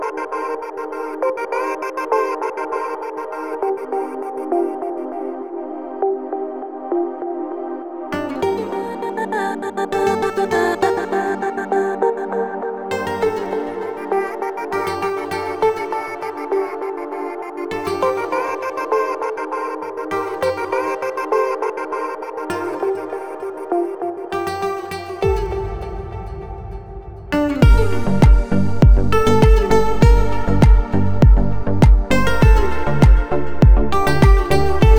Жанр: Танцевальная музыка
# Dance